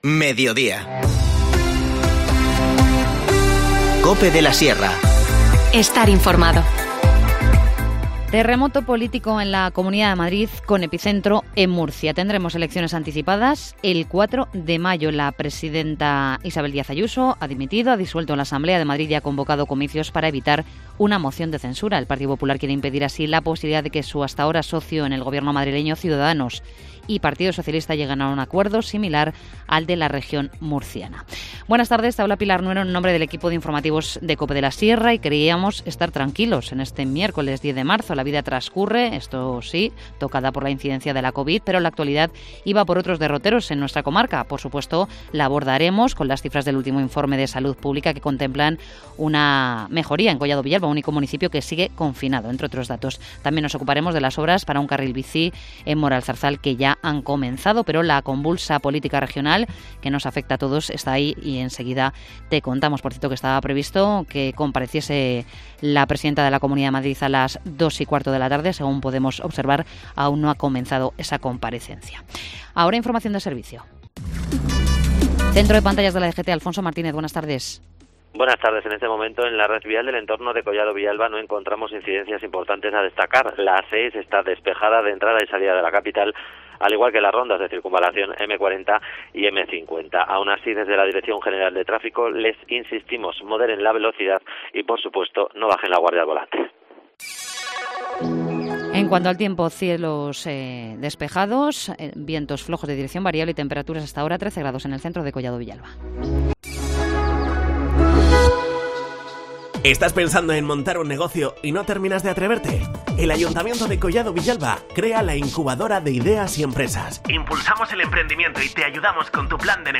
Informativo Mediodía 10 marzo
INFORMACIÓN LOCAL